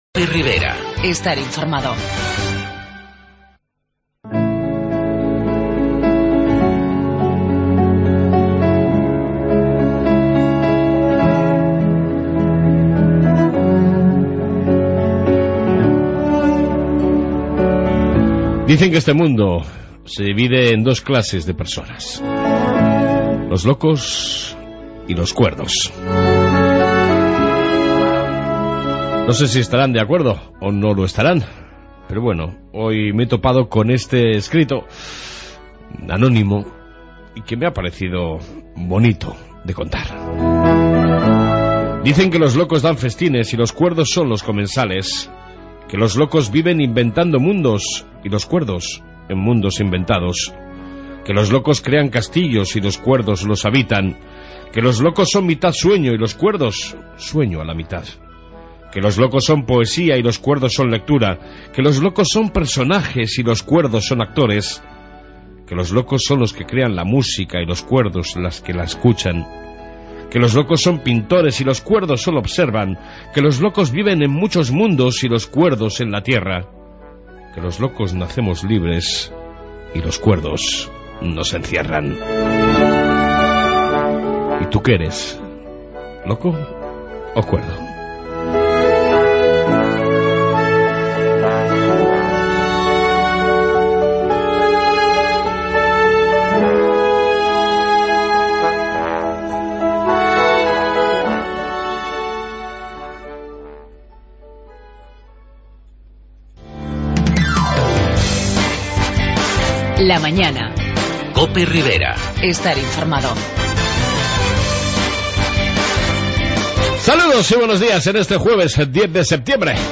Reflexión Matinal, Información Policía municipal y amplia entrevista con el concejal de economía y hacienda Jose Angel Andres Gutierrez para hablar de los primeros días de gobierno y algunos asuntos de actualidad...